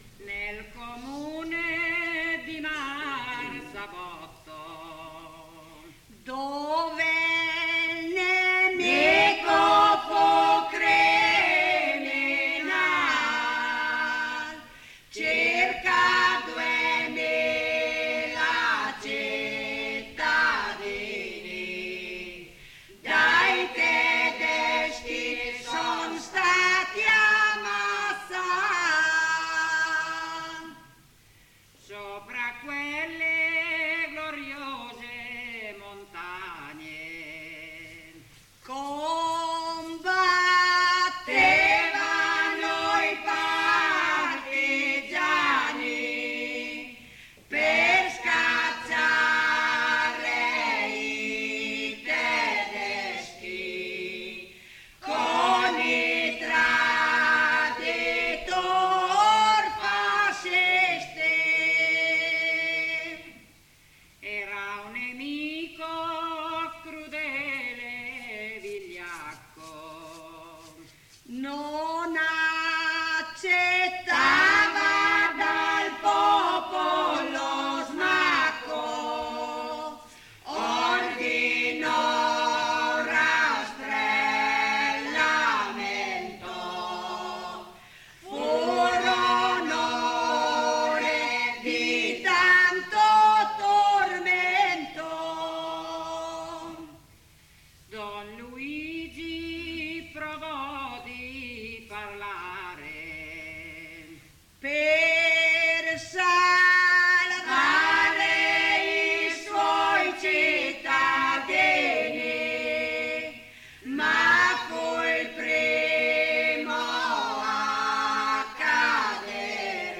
Registrazioni dal vivo e in studio, 1982 circa